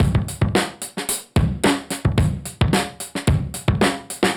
Index of /musicradar/dusty-funk-samples/Beats/110bpm/Alt Sound